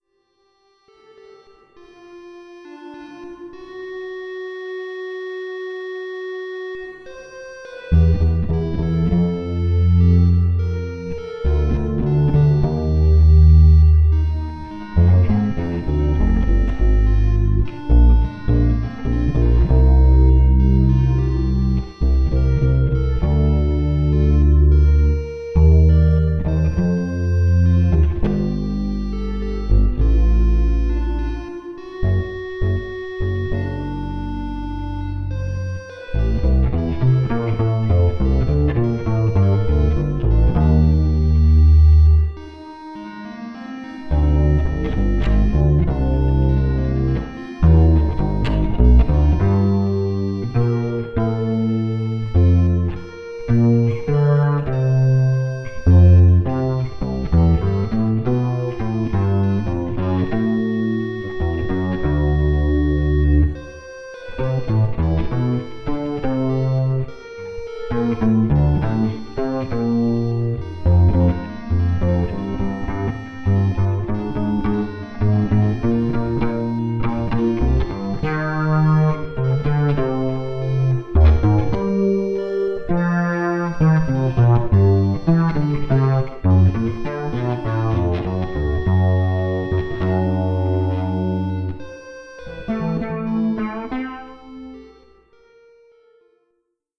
[♪] The Moon in Winter new StudB & Arduino Piano with MIDI Strings '25.09.06, 20 宅録
繰り返すメロディの上に、自分のベースソロを重ねてみました。
ベースは「Audacity」で録音した後、コンプかけてコーラスを、と思ったらエフェクトにコーラスが無かったのでビッチシフターで代用してコーラス効果をかけています。
で、ベースとミックスして最後に軽くリバーブ、って感じです。